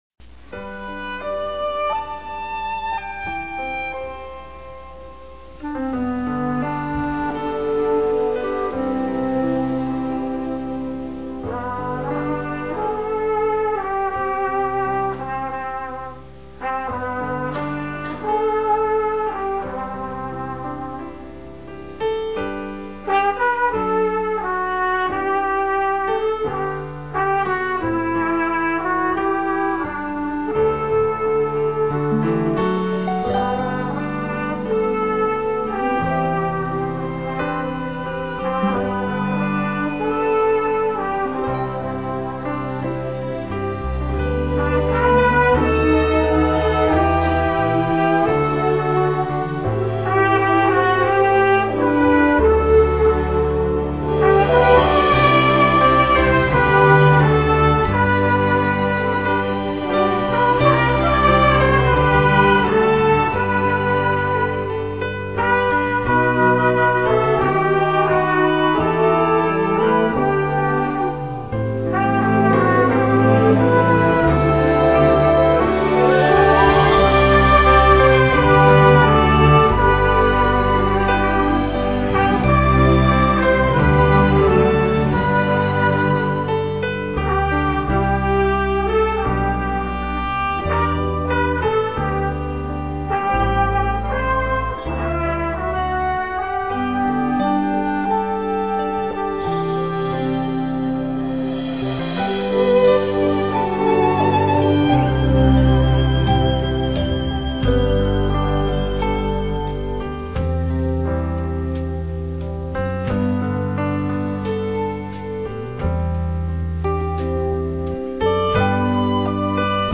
vocal
trumpet
ABC Choir and Children's Choir